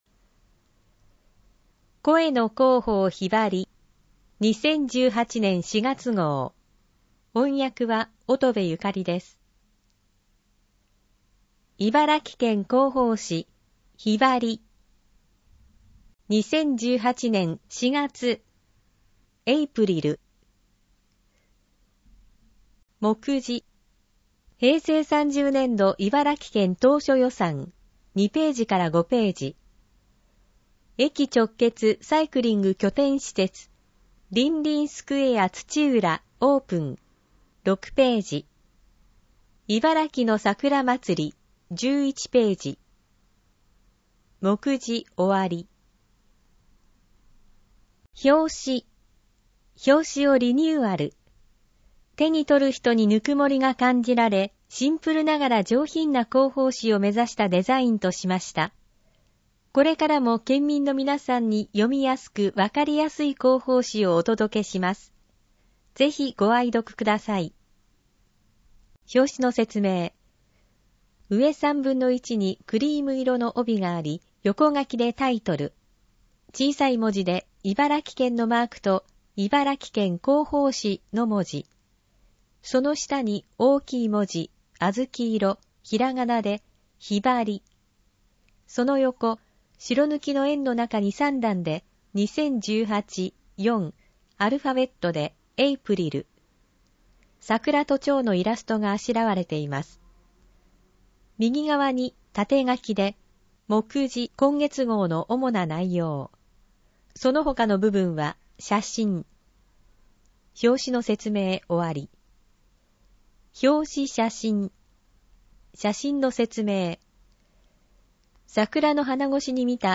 音声版・点字版 視覚障害の方を対象に音声版 も発行しています。